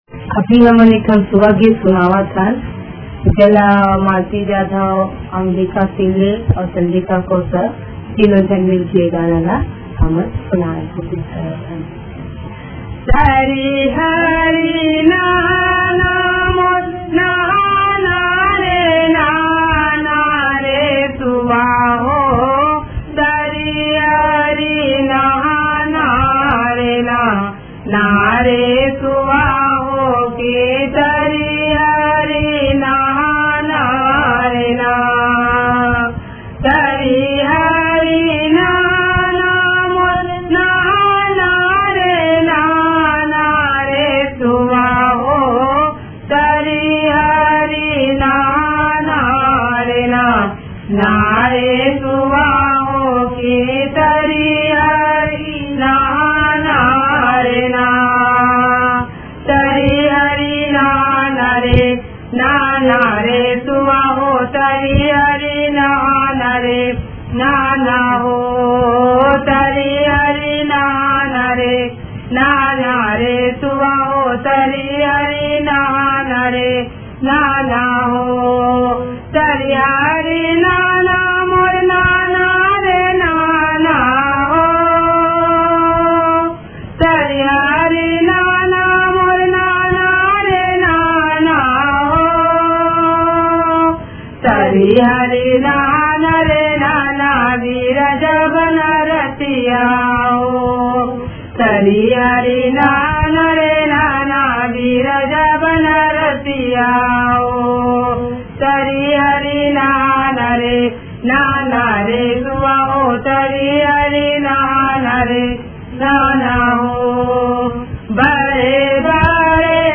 Chhattisgarh
सुआ गीत
सुआ गीत हमेशा एक ही बोल से शुरु होता है और वह बोल हैं -
गीत की गति तालियों के साथ आगे बढ़ती है।